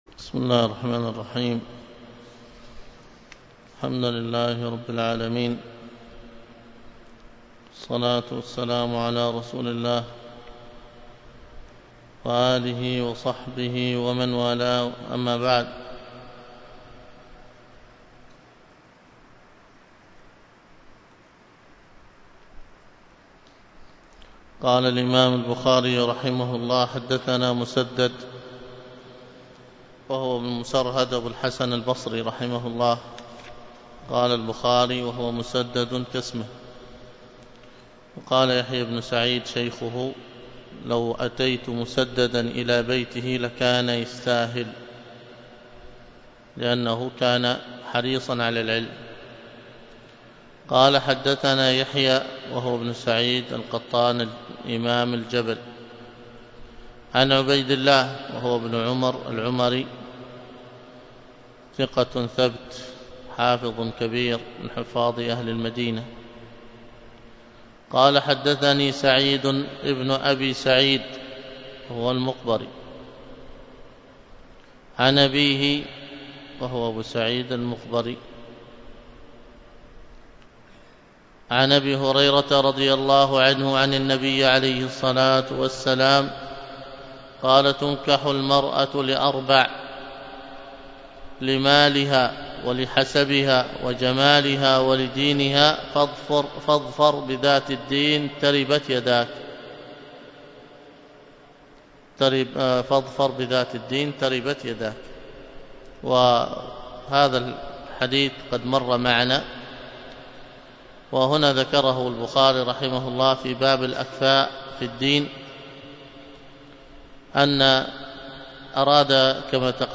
الدرس في كتاب النكاح من صحيح البخاري 24